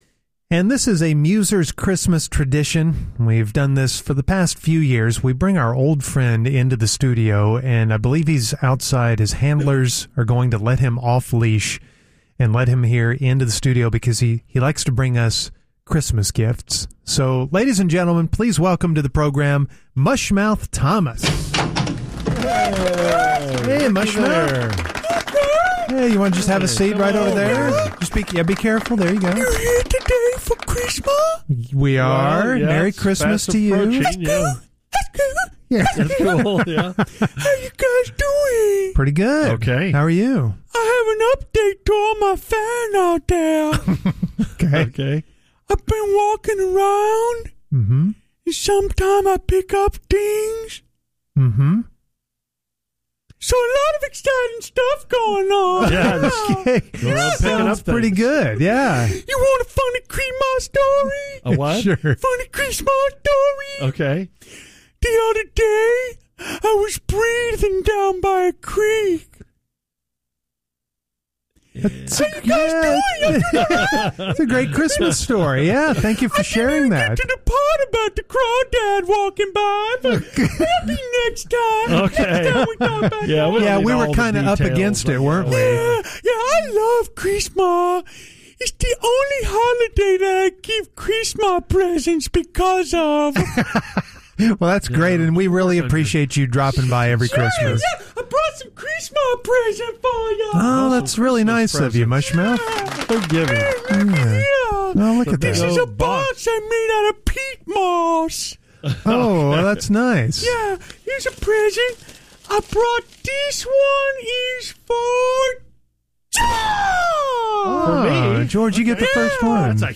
sings a carol